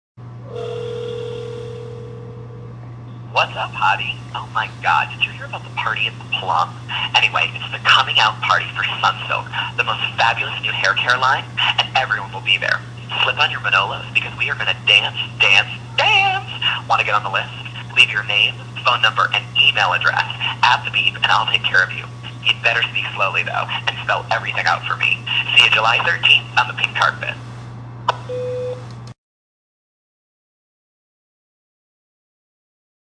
[rising to what I think is called a fever pitch]
I wish I could say it ended there, but this insane RSVP recording is part of U.S. product launch with so many gay jokes I’d be offended if I didn’t feel sorry for them.